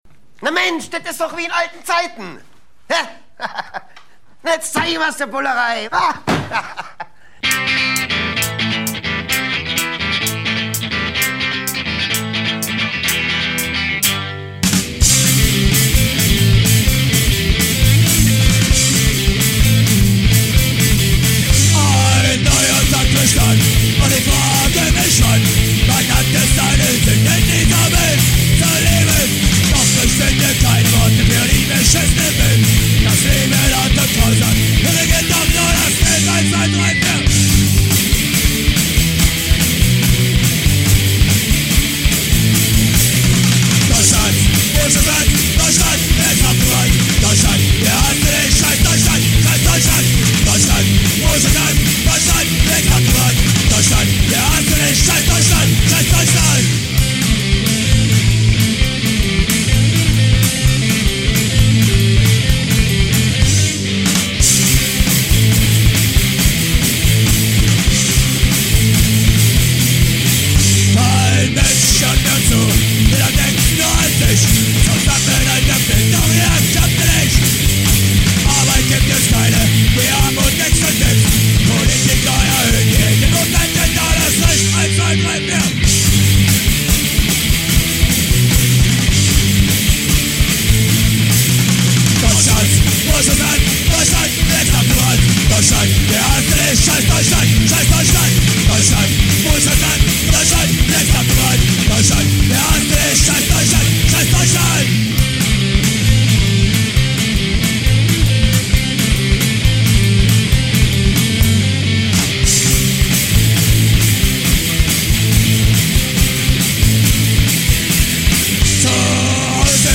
Deutschpunk-Band